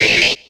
Cri de Gobou dans Pokémon X et Y.